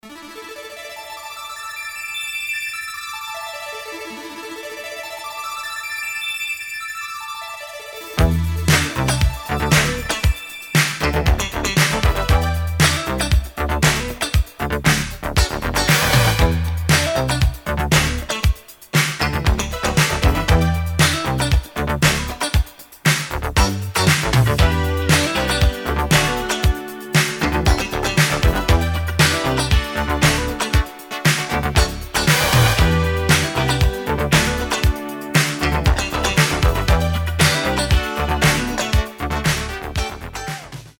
Пост-диско от поп-королевы